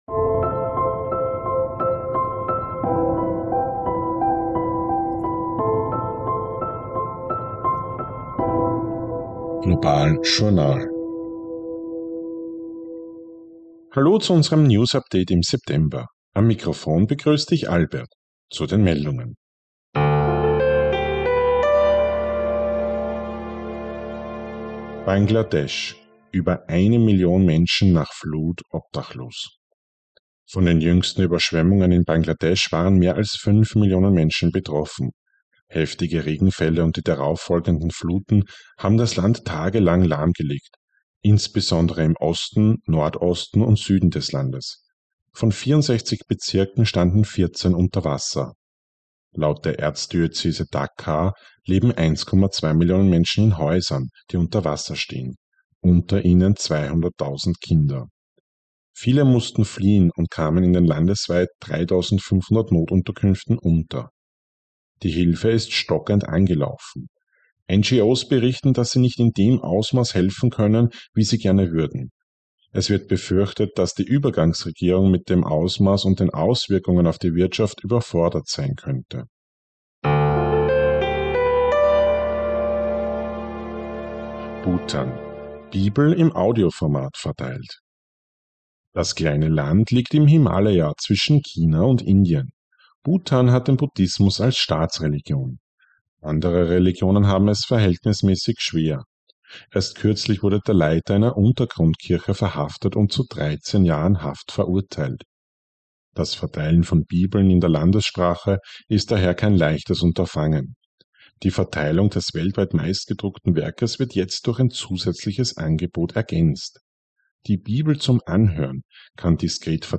News Update September 2024